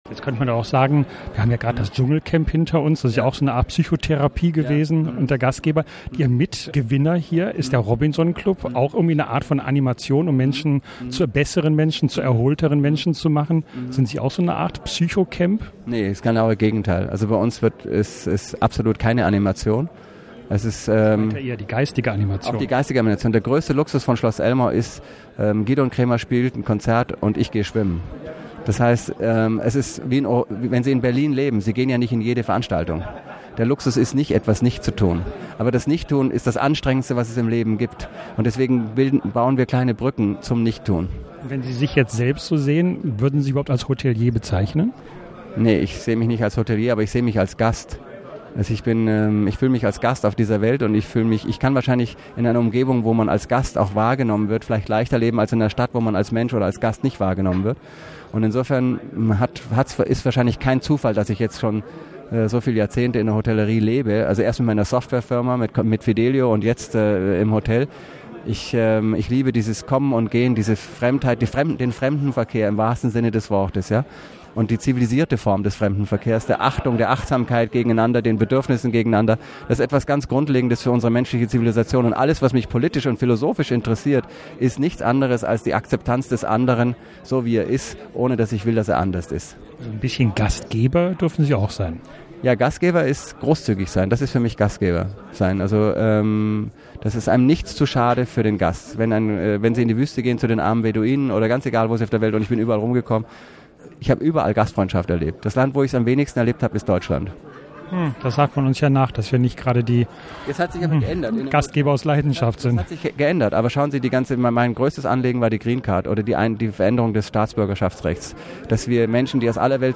Freuen Sie sich auf eine hochsympathische, kluge Plauderei.